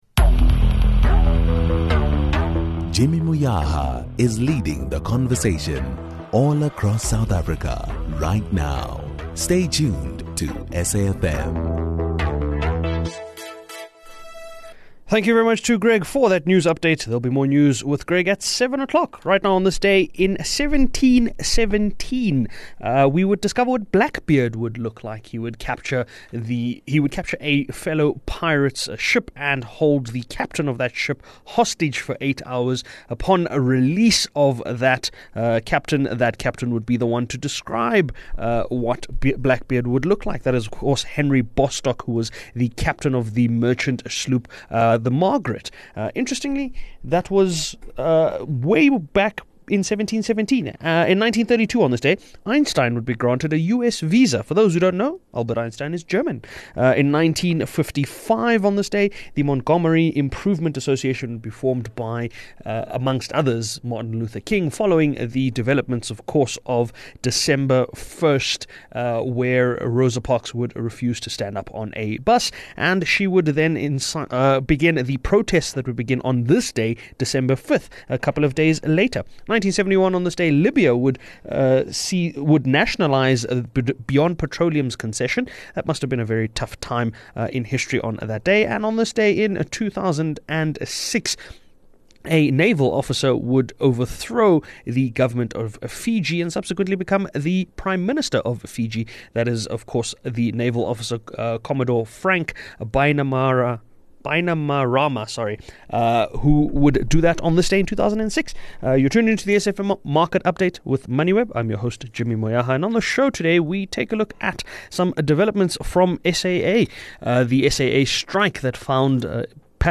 The programme is broadcasted Monday to Thursday nationwide on SAfm (104 – 107fm), between 18:00 and 19:00.